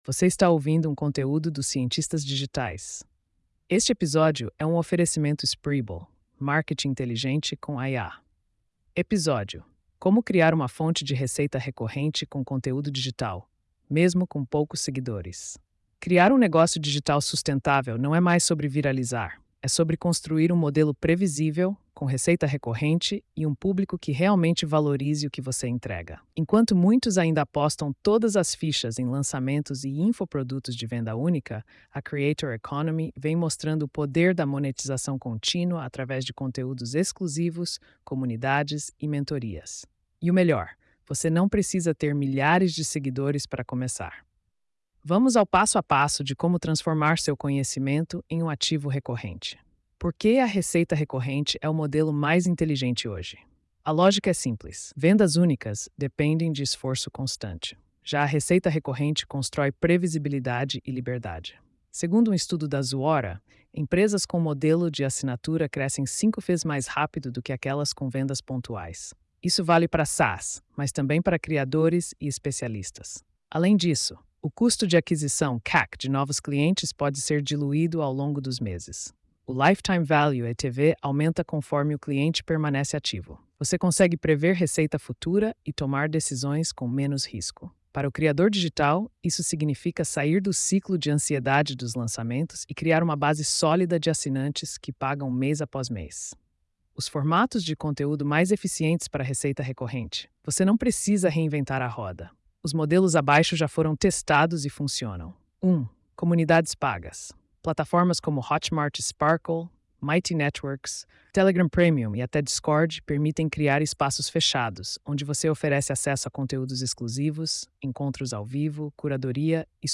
post-4087-tts.mp3